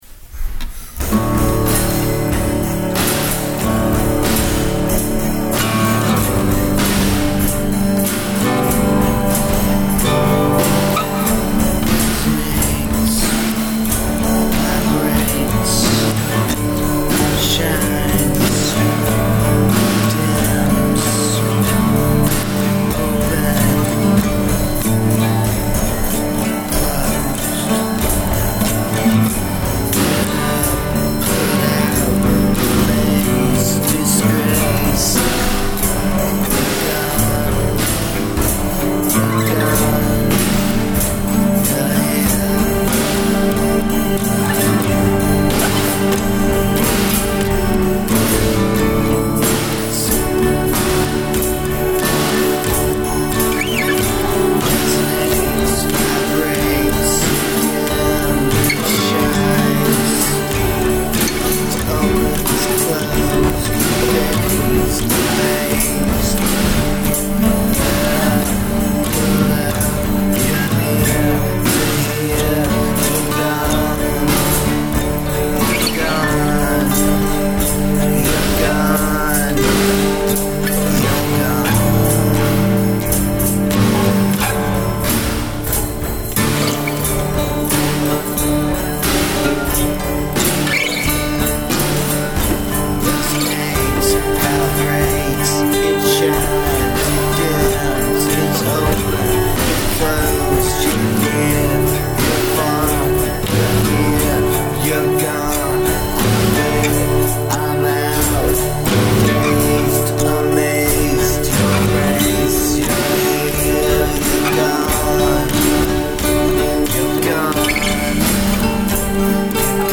I was asked to do a quick re-mix of the song with the vocals more prominent in the mix.